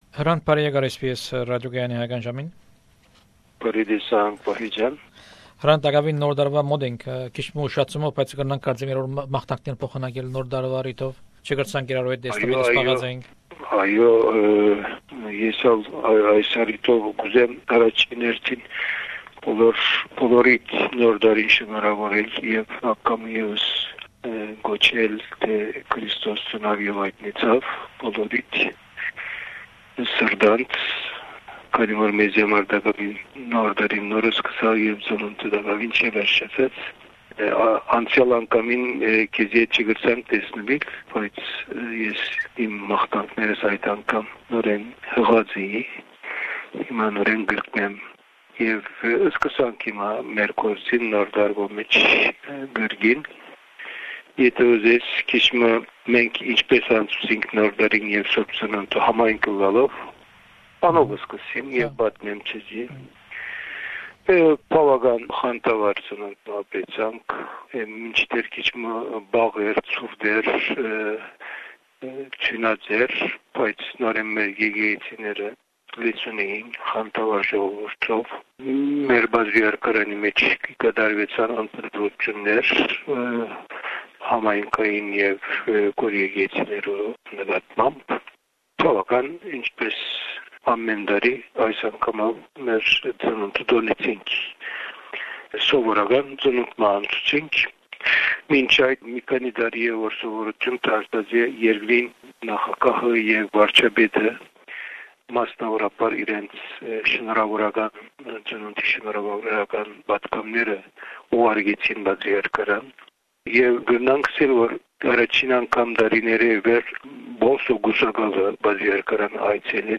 This is the seventh interview with the editor in chief of Agos newspaper in Istanbul, Hrant Dink in January 2004.